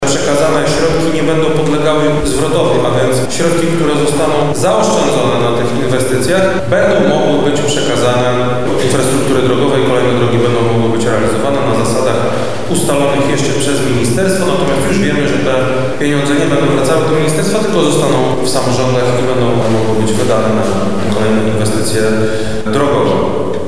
O tym, co stanie się z resztą środków mówi Krzysztof Komorski, wojewoda Lubelski: